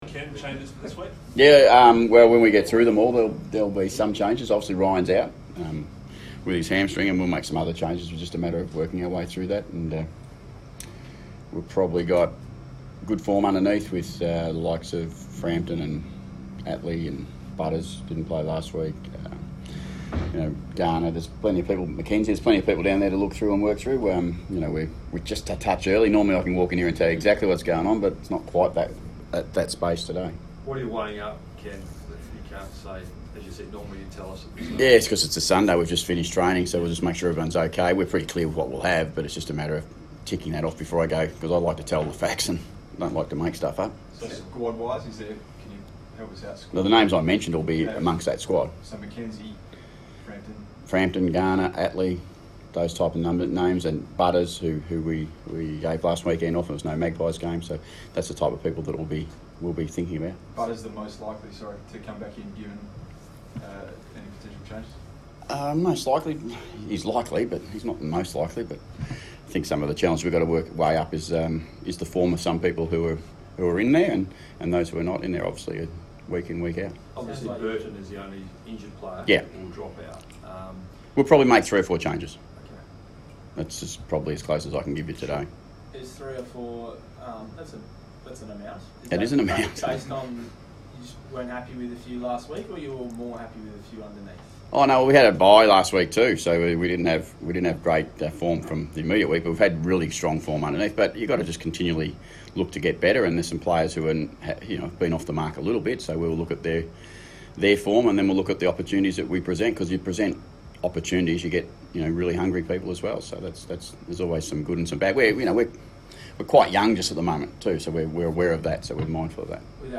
Ken Hinkley press conference - Thursday 16 May, 2019